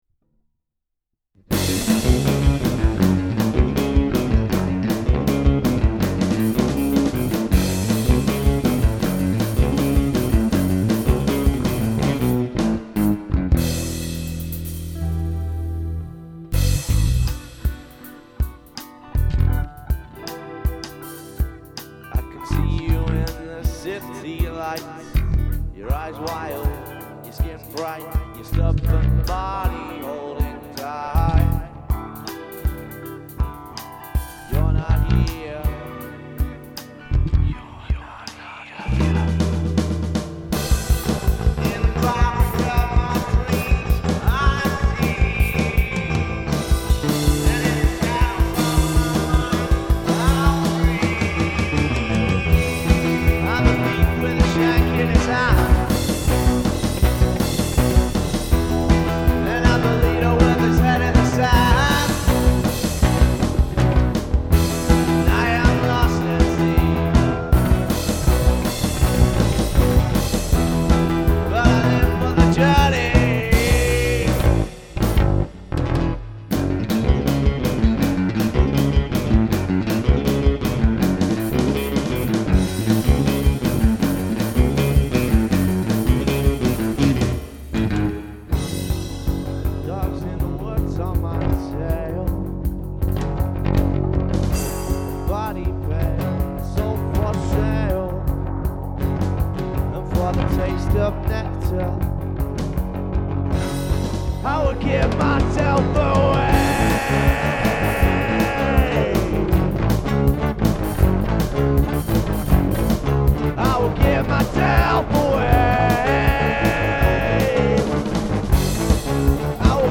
Experimental
Prog rock